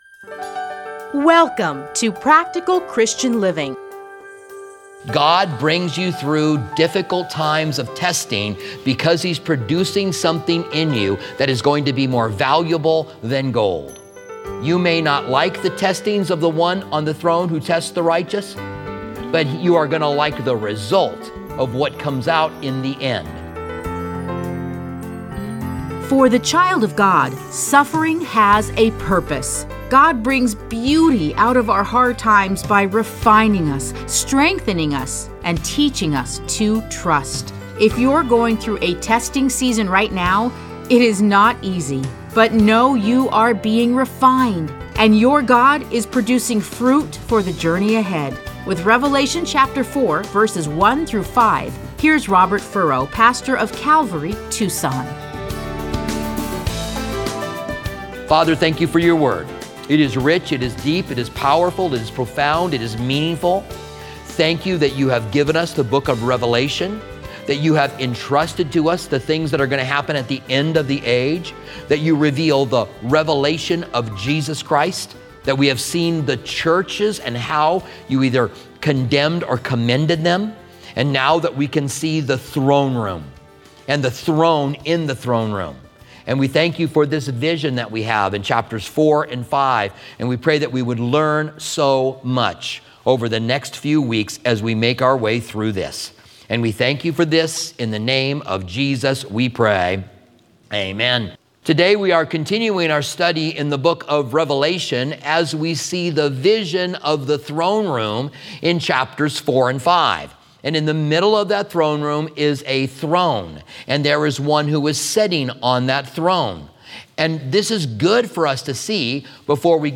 Listen to a teaching from Revelation 4:1-5.